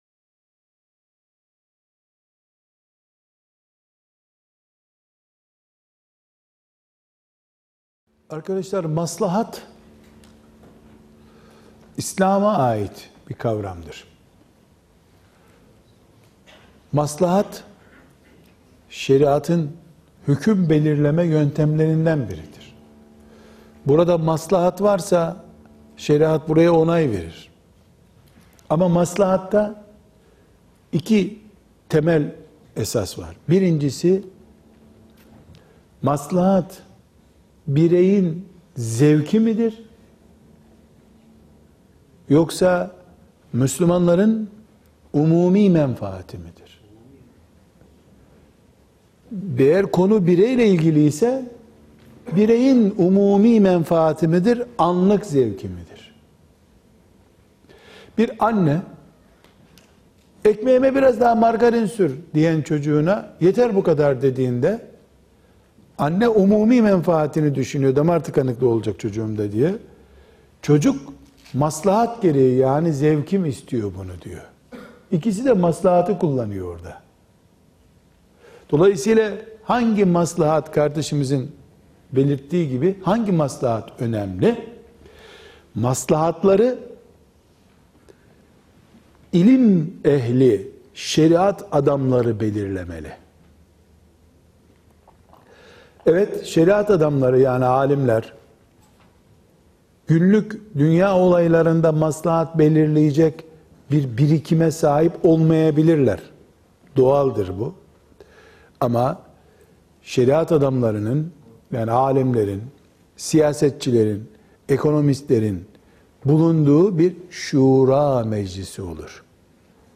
95) Maslahat Ama Kime ve Neye Göre? (Gençlerle Soru-Cevap)